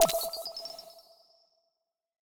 overlay-pop-in.wav